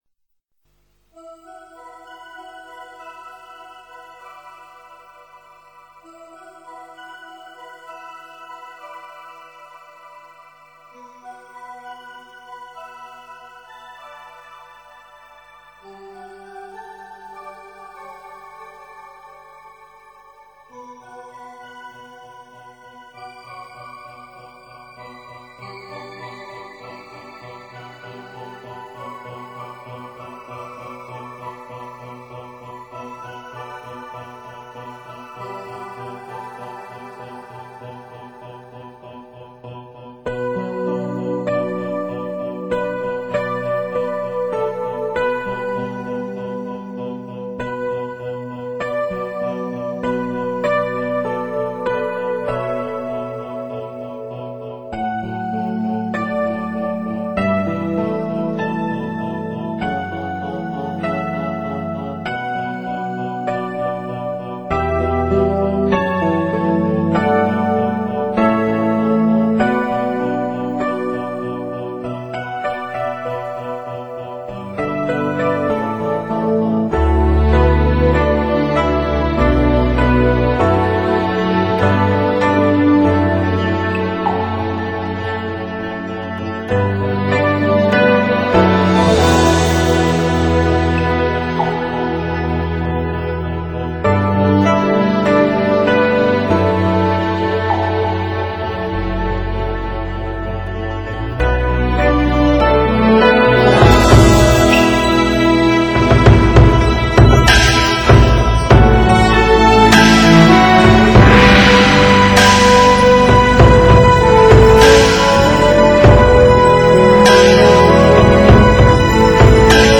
背景音乐二